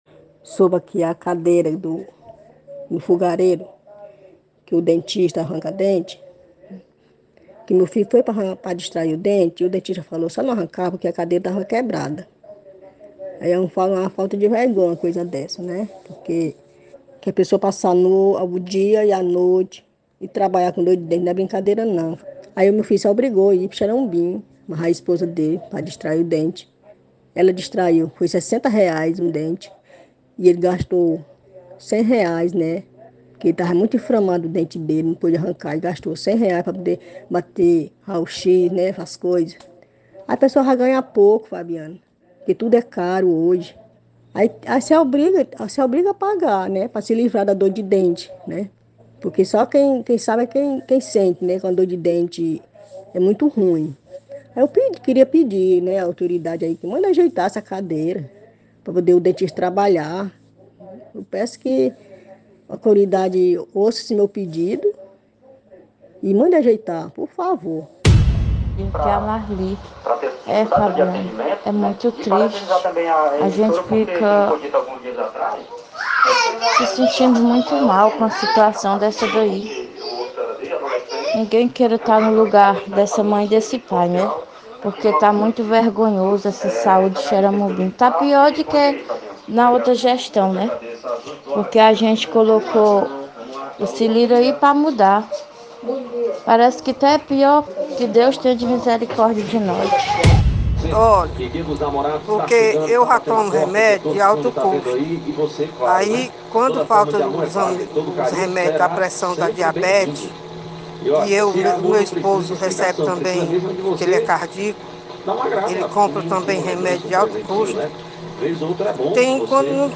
Da cadeira quebrada do dentista até a falta de testes de diabetes em postos de saúde. Esses foram os relatos encaminhados nesta quarta-feira, 9, ao programa SerTão Conta Mais, da SerTão TV e rádio Campo Maior AM 840.
Um dos relatos é de uma ouvinte da comunidade de Fogareiro.